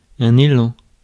Ääntäminen
IPA : /ɛlk/ US : IPA : [ɛlk]